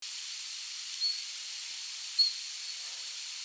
In the field all calls sounded like short, evenly-pitched monosyllables ('eeep' or 'iiihp') with a somewhat plaintive quality, thus matching Siberian Chiffchaff. The calls resembled one call of Dunnock or a straighter, higher-pitched and less fluted version of a Bullfinch call.
There are six calls in this sequence, at 1.7s, 8.5s (very quiet and easily missed), 10.7s, 15.7s, 20.4s and 25.7s. The calls at 1.7s and at 10.7s have been extracted and transferred adjacently to a new file
Each call is very short (around 0.2s) and will sound very similar to most people. Those with a very good ear may detect that the first call has a 'straighter' (more even) pitch, matching the classic tristis profile, while the second has a slightly more arched, weakly inflected profile.
Figure 1. Two variant calls given by Siberian Chiffchaff, Warks, January 2013.
tristisLadywalktwocallstrunc.mp3